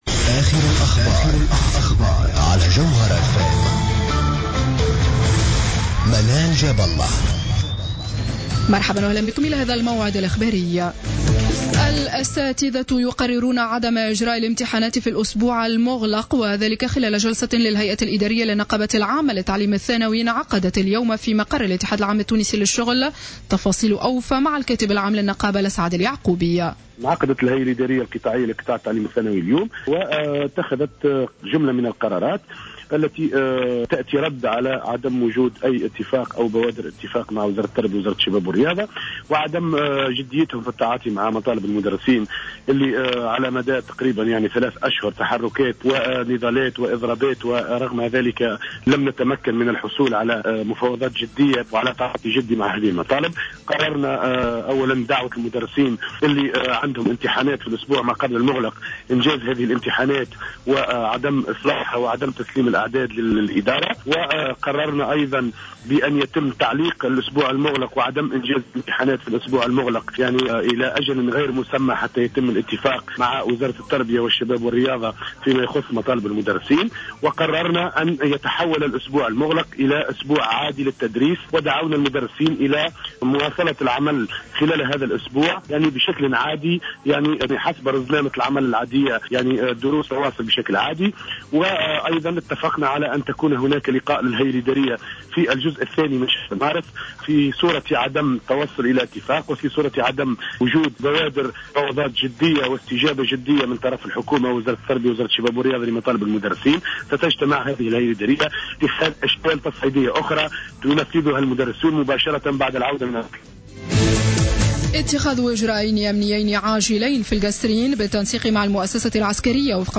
نشرة أخبار السابعة مساء ليوم الأحد 22 فيفري 2015